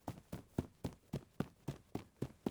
02_孤儿院走廊_小孩踢球.wav